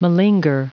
Prononciation du mot malinger en anglais (fichier audio)
Prononciation du mot : malinger